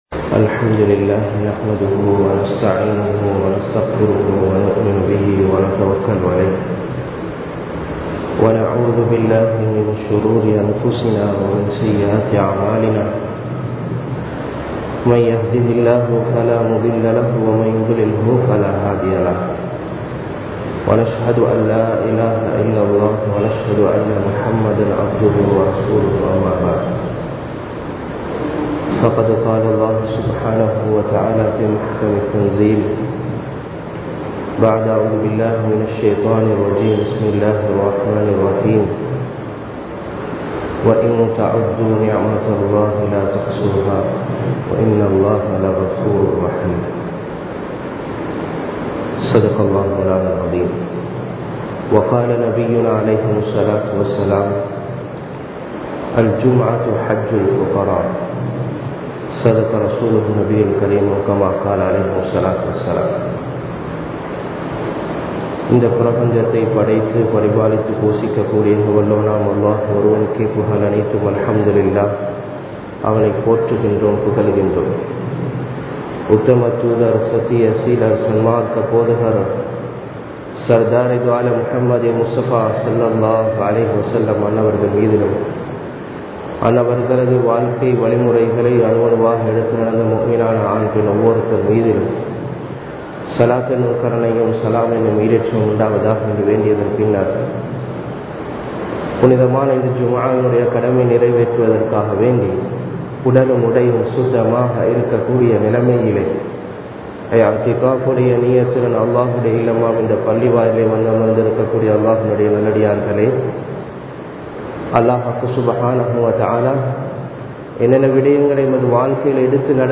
நிஃமத்துகள் | Audio Bayans | All Ceylon Muslim Youth Community | Addalaichenai
Avissawella, Nappawela Fowz Jumuah Masjith 2021-07-30 Tamil Download